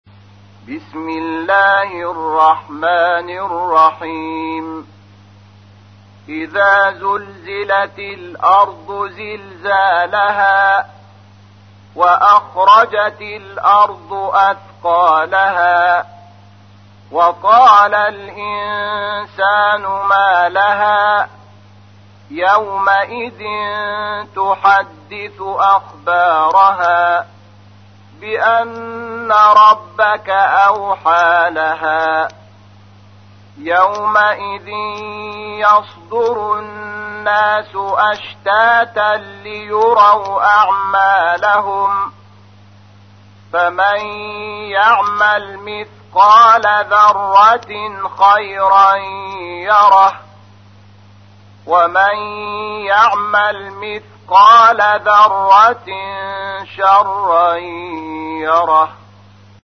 تحميل : 99. سورة الزلزلة / القارئ شحات محمد انور / القرآن الكريم / موقع يا حسين